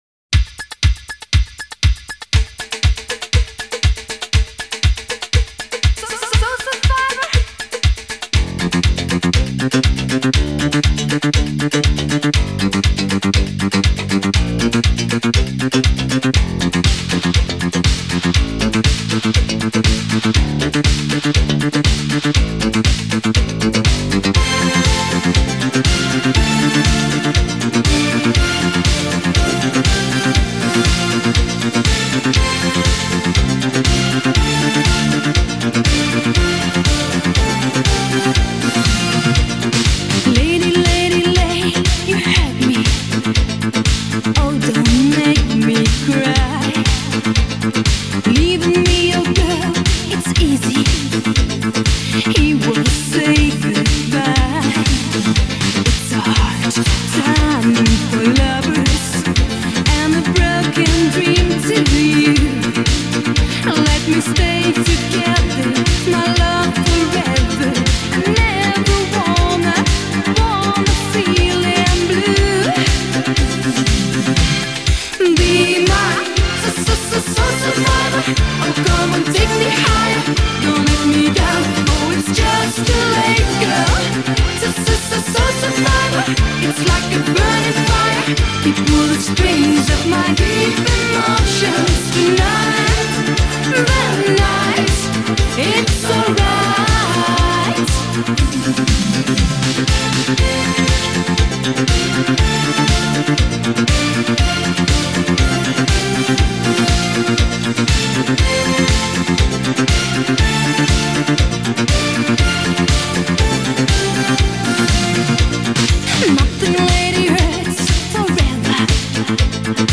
пронизана энергией и страстью
стала одной из самых ярких звезд в мире евродиско 80-х